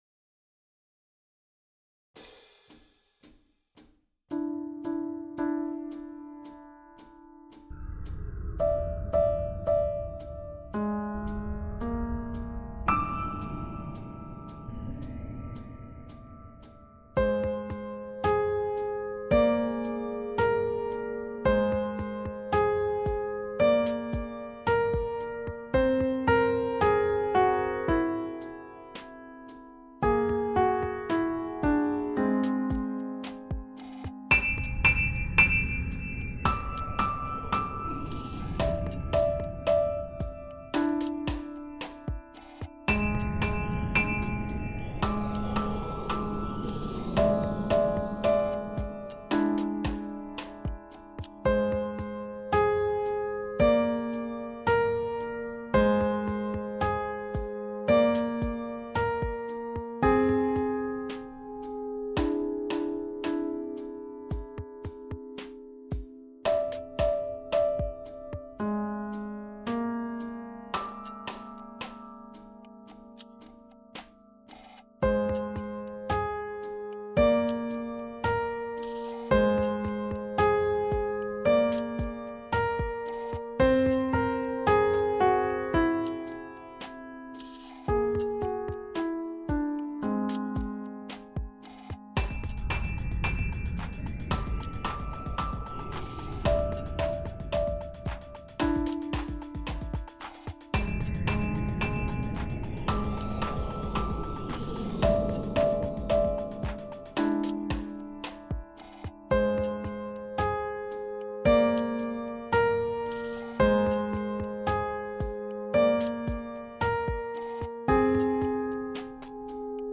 • Key: D Minor (with whole-tone scale color)
• Time signature: 4/4